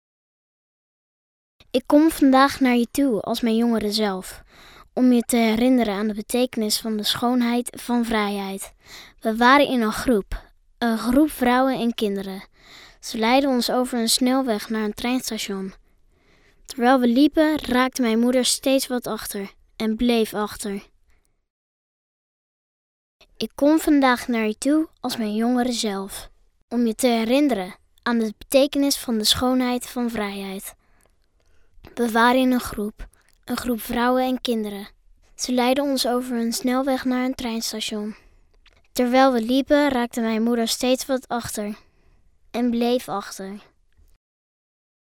Dutch male voices